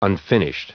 Prononciation du mot unfinished en anglais (fichier audio)